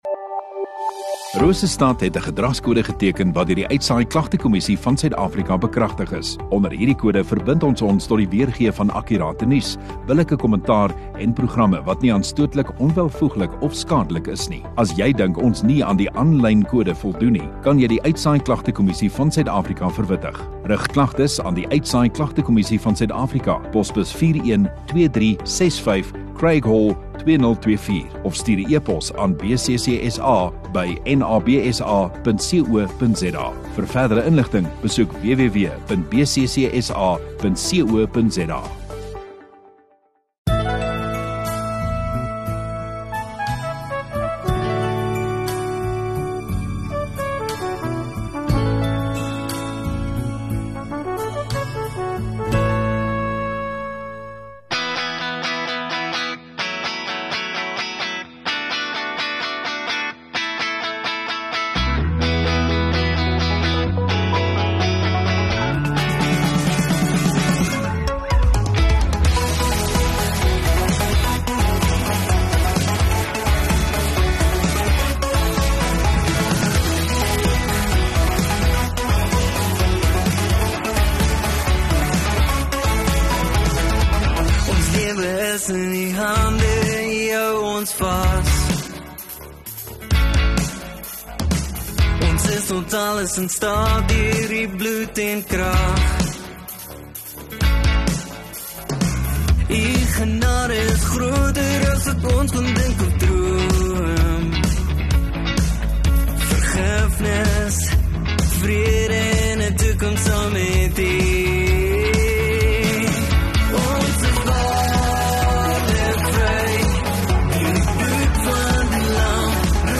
10 Nov Sondagaand Erediens